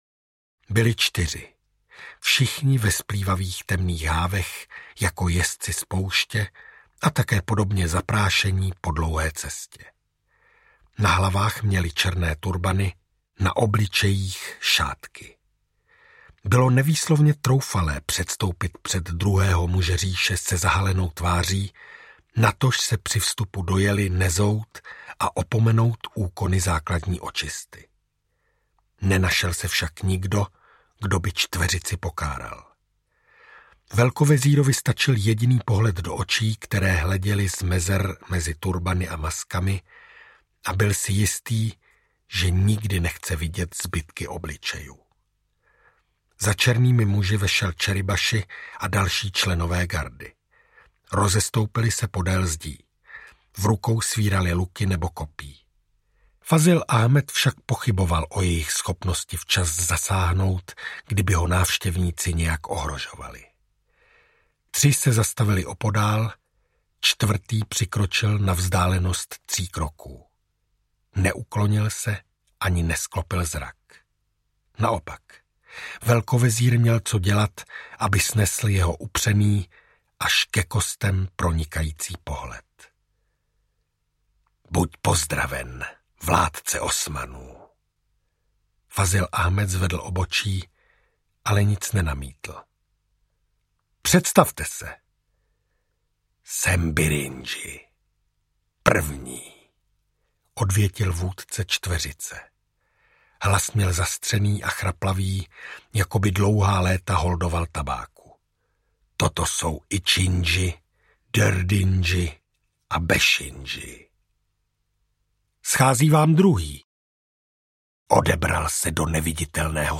Ďáblova pevnost audiokniha
Ukázka z knihy